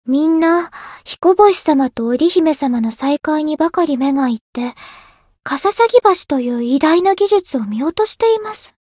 灵魂潮汐-爱莉莎-七夕（摸头语音）.ogg